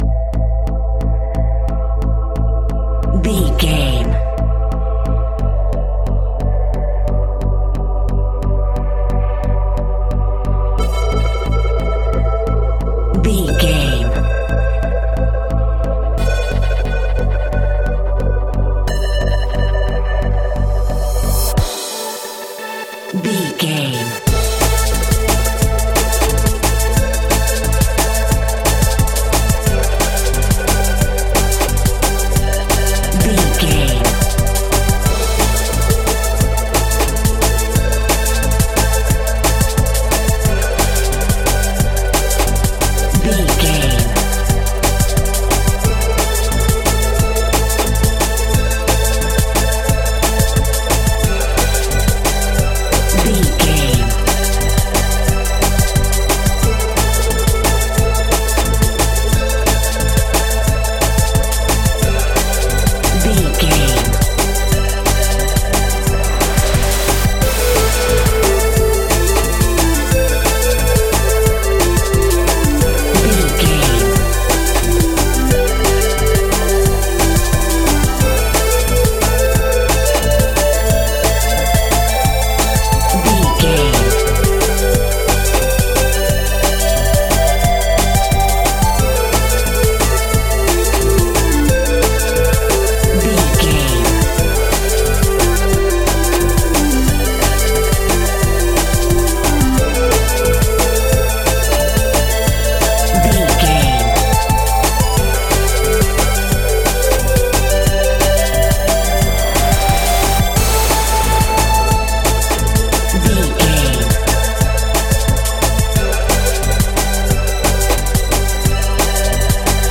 Aeolian/Minor
Fast
aggressive
dark
driving
energetic
groovy
drum machine
synthesiser
electronic
sub bass
Neurofunk
instrumentals
synth leads
synth bass